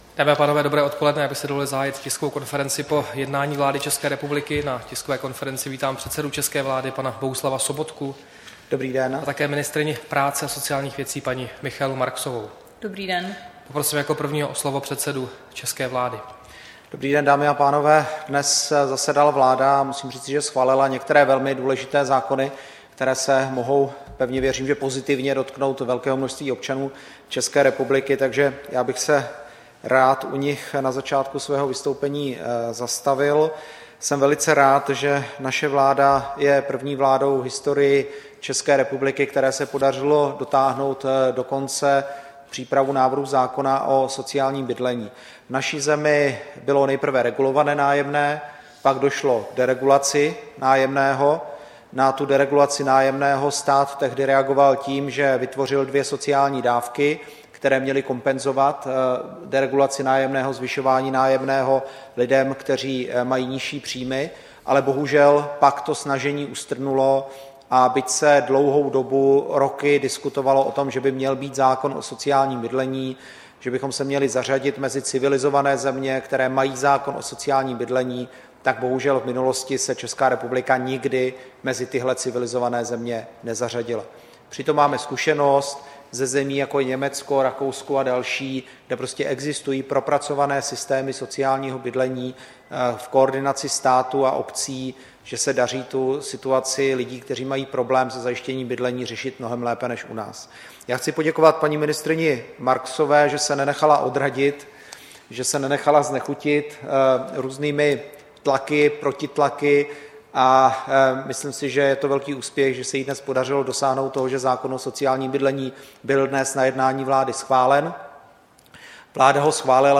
Tisková konference po jednání vlády, 8. března 2017
Bohuslav Sobotka, předseda vlády ČR: Dobrý den, dámy a pánové.